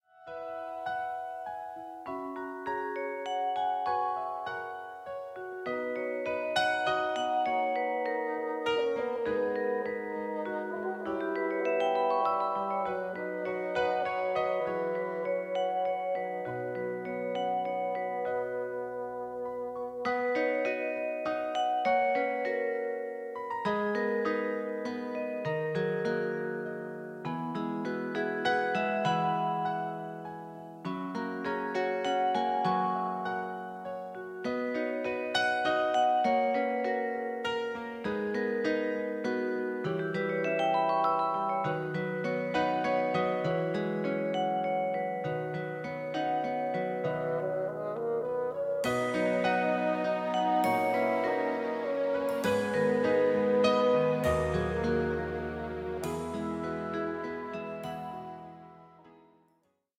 Das Playback-Album zur gleichnamigen Produktion.
Playback ohne Backings, gleiche Reihe 2,99 €